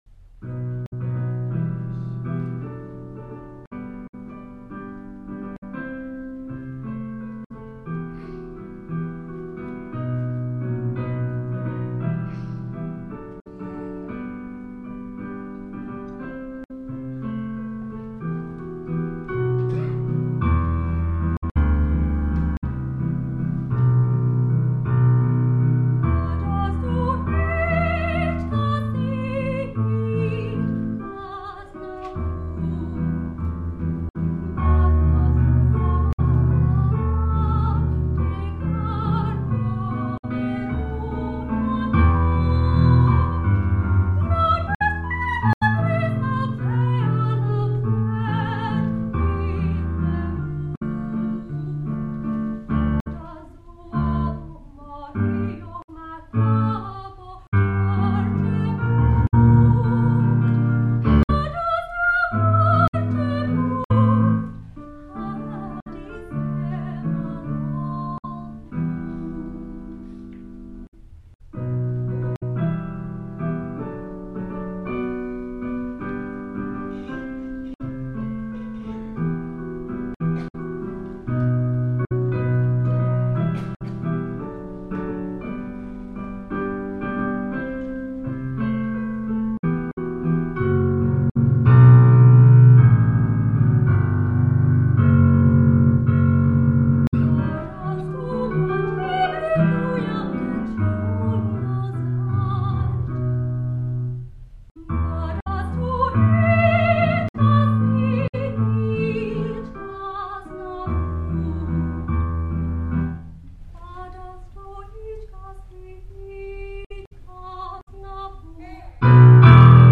Recorded live at the SFU choir concert
singing and the composer at the piano